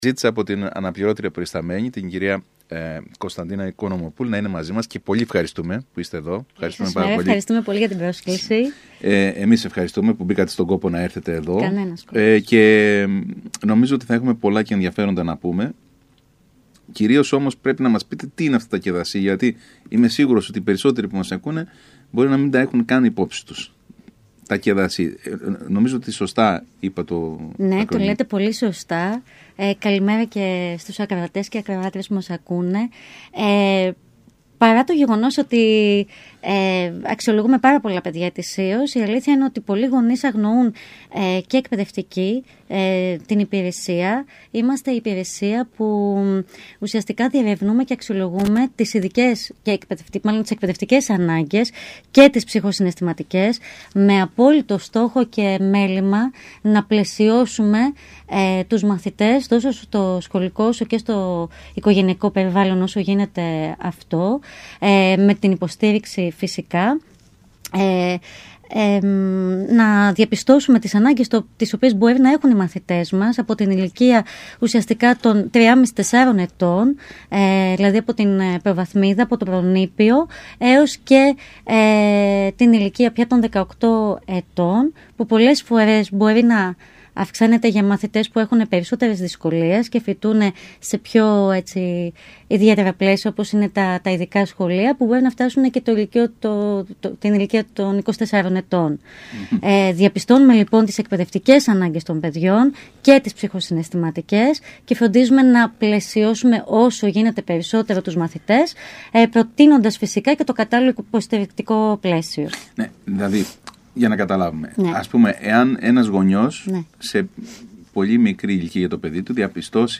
μίλησε στον ΣΚΑΙ Κρήτης